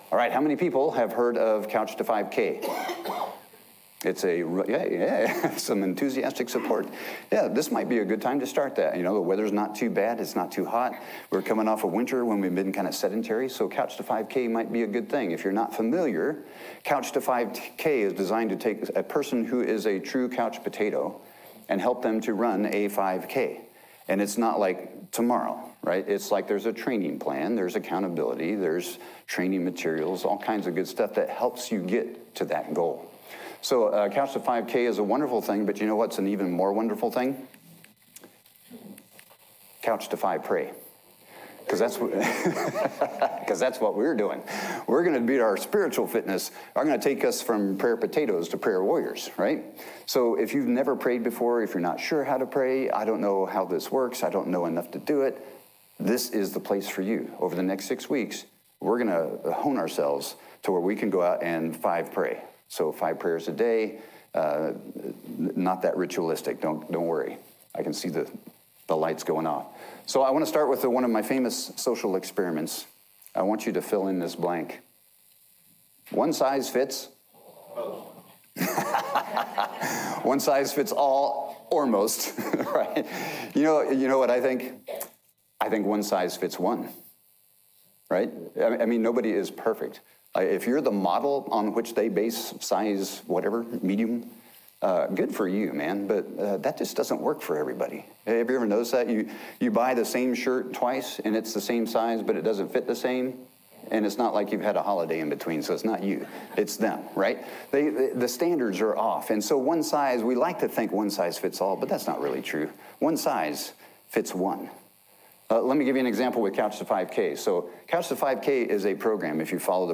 Sermons
audio-sermon-one-size-fits-_____.m4a